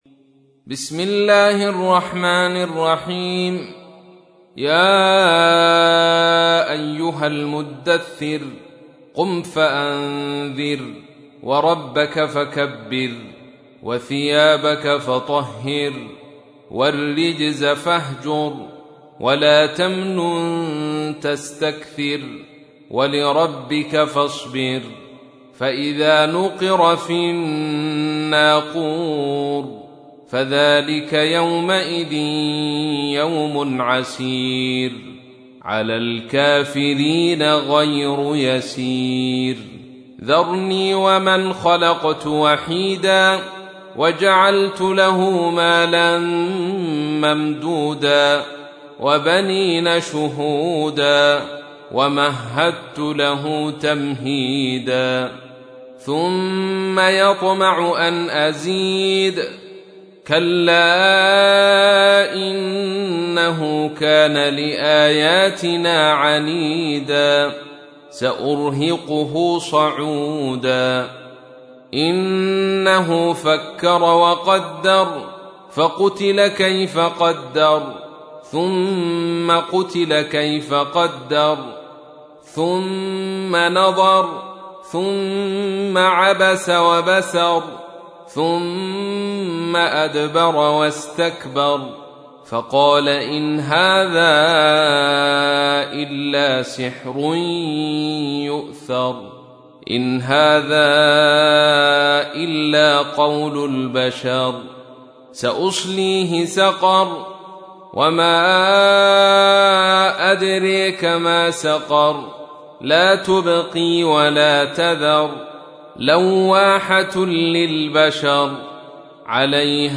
تحميل : 74. سورة المدثر / القارئ عبد الرشيد صوفي / القرآن الكريم / موقع يا حسين